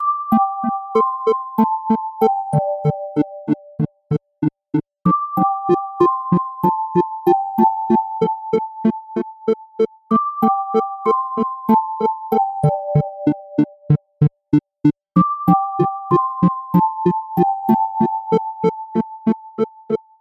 描述：领先的合成器，适用于寒战、嘻哈音乐。
Tag: 95 bpm Chill Out Loops Synth Loops 3.40 MB wav Key : E